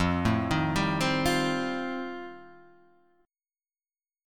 E#79 chord